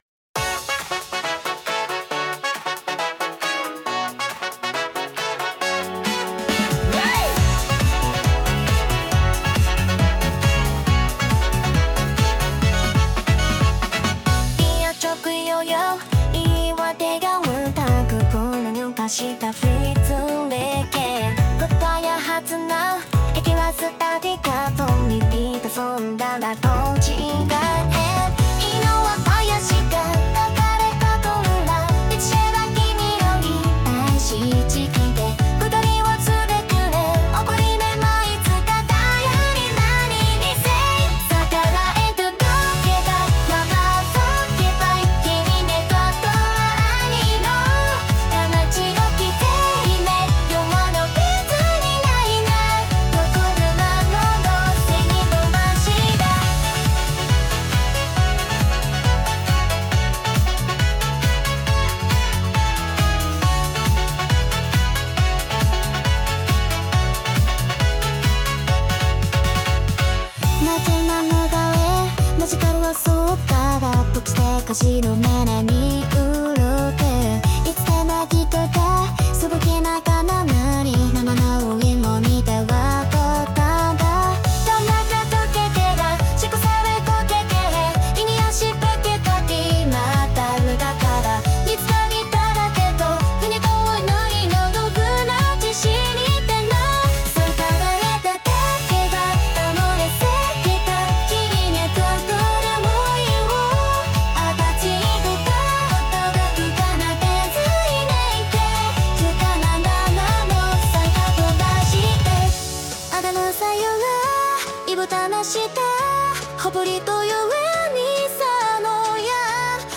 • ジャンル：エラーポップアイドル
• 声：明るくハスキー／喋りが高速気味